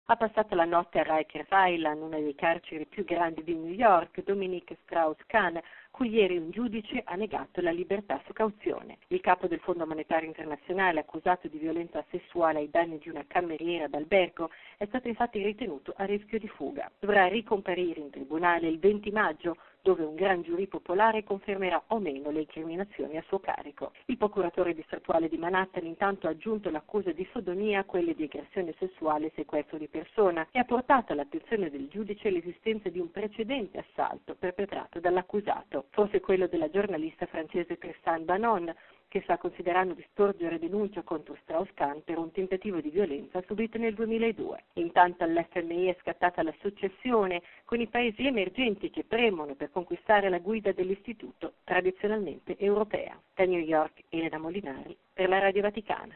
La giustizia americana ha negato la libertà su cauzione al direttore del Fondo monetario internazionale, che rischia oltre 74 anni di carcere per doppia violenza sessuale, tentato stupro e sequestro di persona. Da New York, ci riferisce